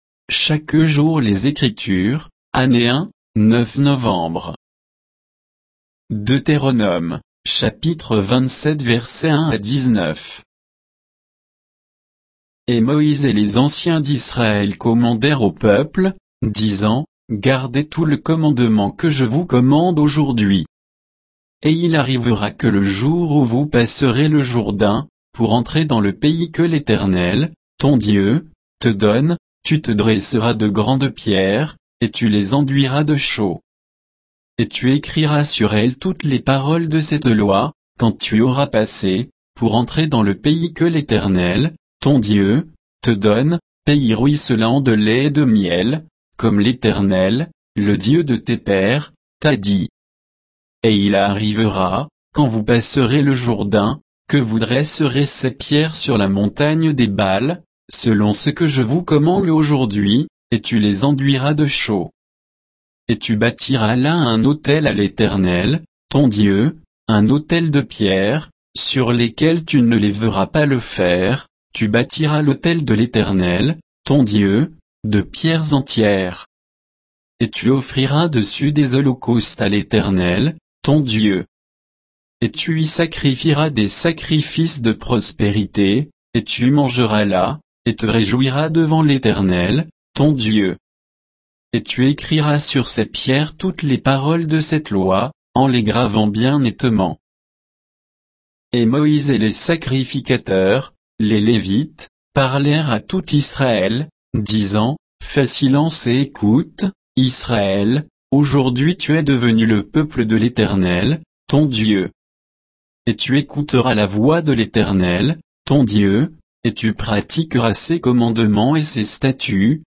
Méditation quoditienne de Chaque jour les Écritures sur Deutéronome 27